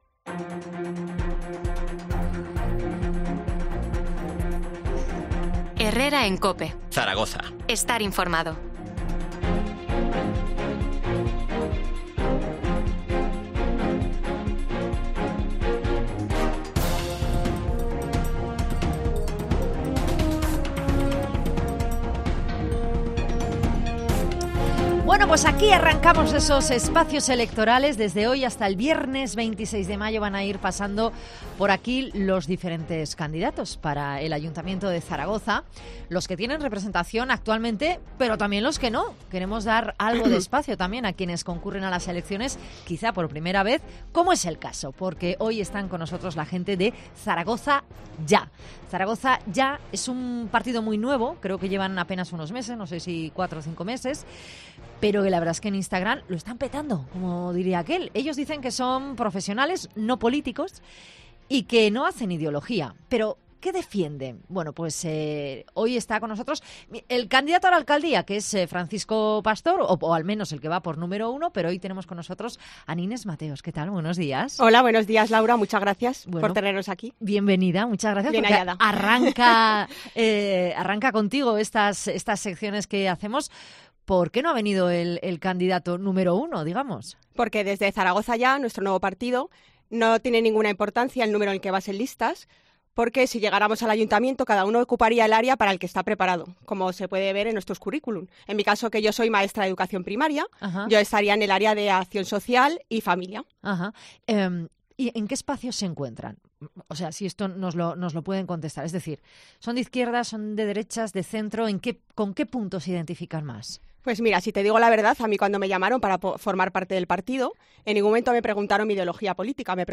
Entrevista
en los estudios centrales de la emisora en Zaragoza